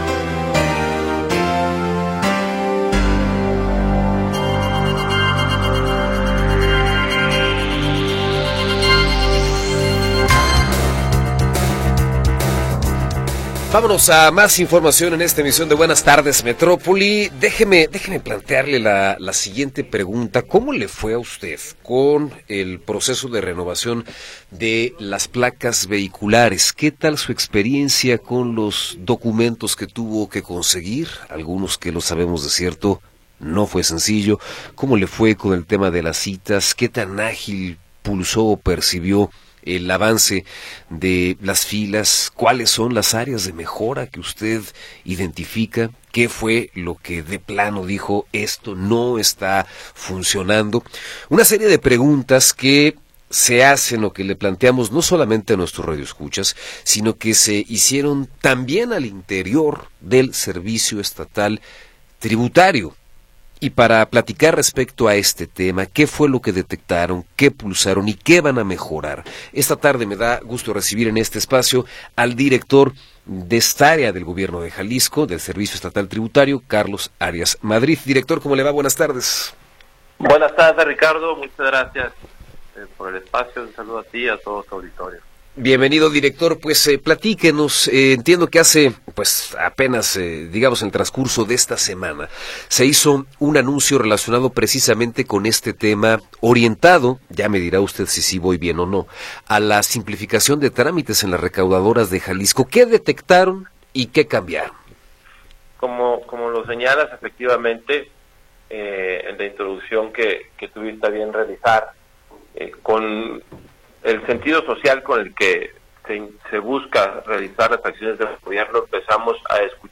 Entrevista con Carlos Arias Madrid